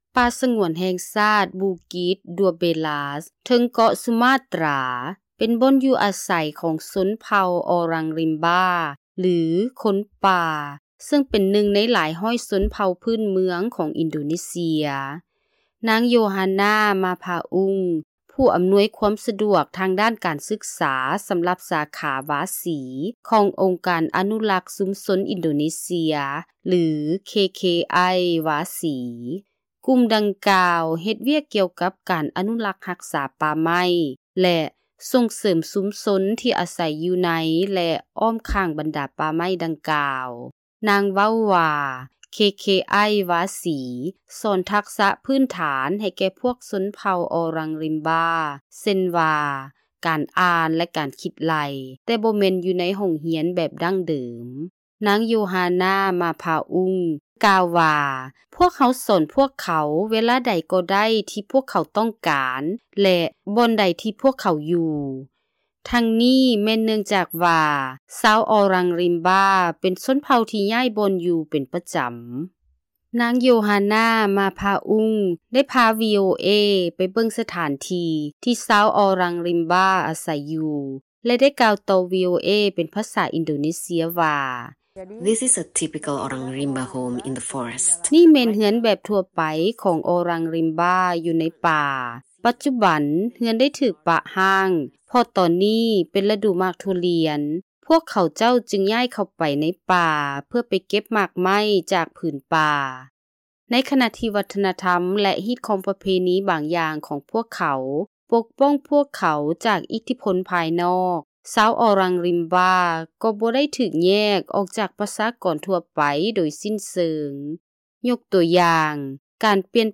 [[pron: Oh-Rung Reem-Ba]]